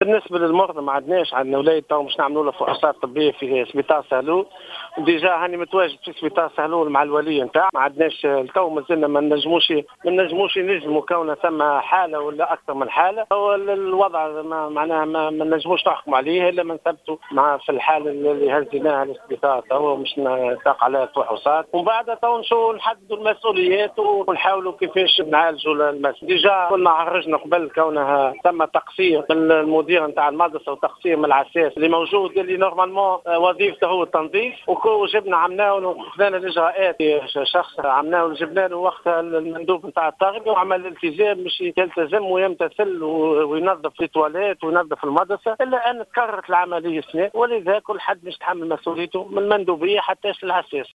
وقال في تصريح لـ "الجوهرة اف أم" إنه لا يمكن الجزم حاليا بإصابة التلميذ من عدمه وانتشار عدواه بين صفوف التلاميذ في مدرسة السد الشمالي سيدي بوعلي، مشيرا إلى أن الطفل مازال يخضع لفحوصات بالمستشفى وأنه (المعتمد) متواجد حاليا رفقة ولي الطفل بالمستشفى.